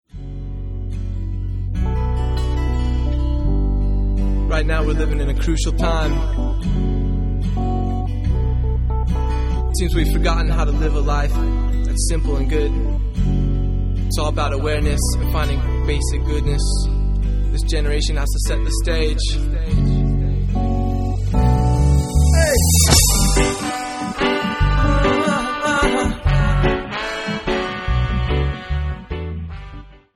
Psychedelic
Reggae